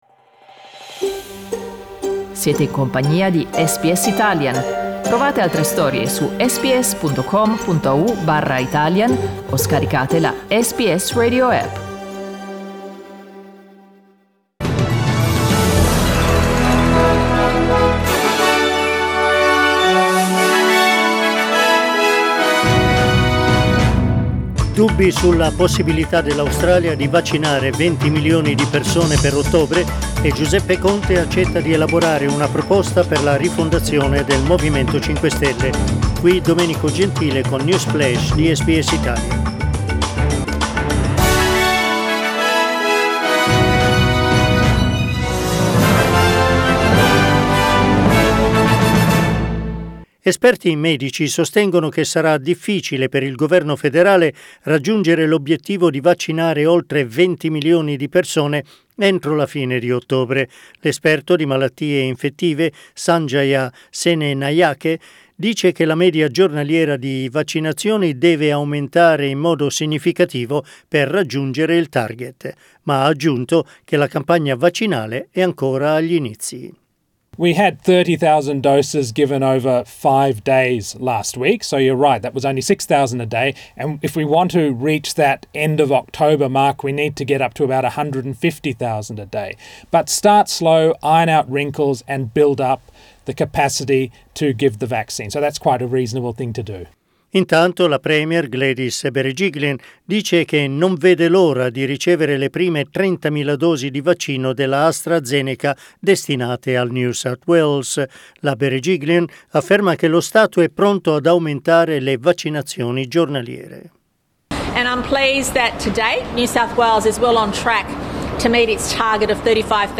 News Flash lunedì 1 marzo 2021